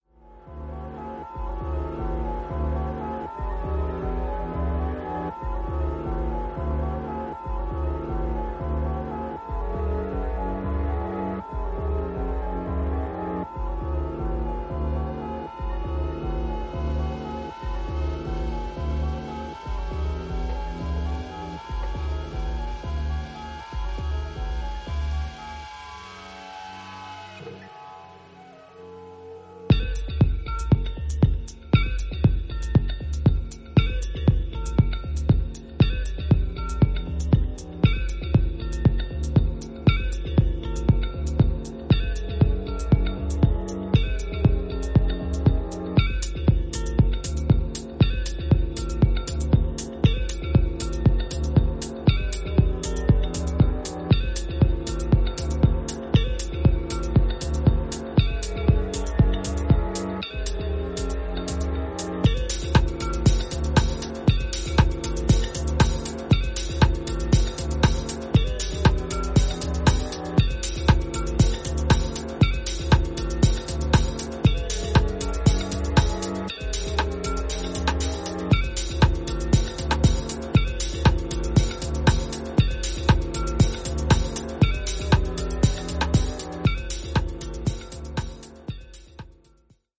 ハウス/ダウンビートを軸にバレアリックな雰囲気を纏った、ノスタルジックで素敵な1枚。